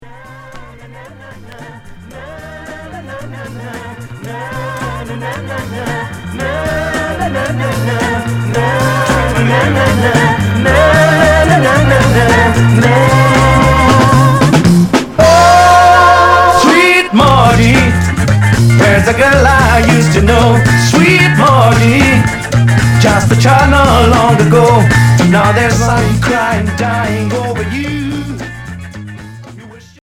Pop progressif